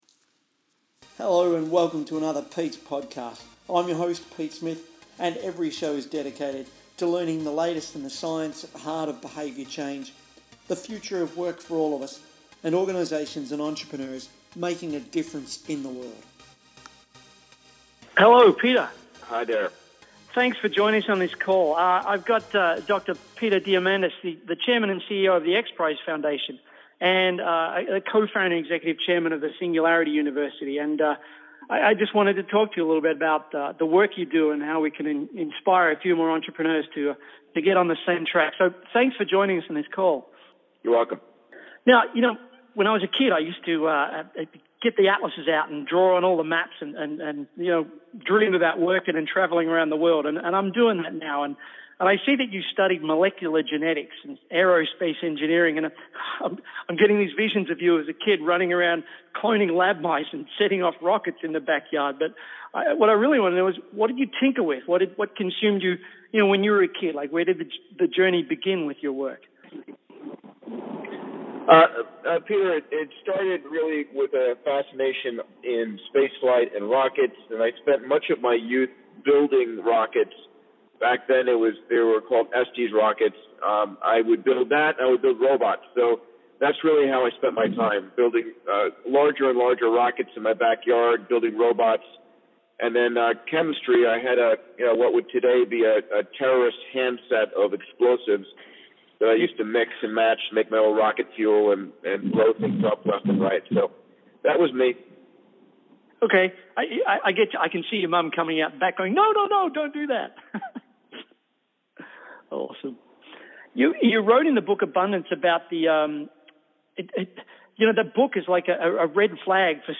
Peter Diamandis is a Greek–American engineer, physician, and entrepreneur best known for being the founder and chairman of the X Prize Foundation, the co-founder and executive chairman of Singularity University and the co-author of the New York Times bestsellers Abundance: The Future Is Better Than You Think and BOLD: How to Go Big, Create Wealth, and Impact the World. In this interview I ask Peter about his early years as an entrepreneur, and how we can all work toward a brighter future and a better world.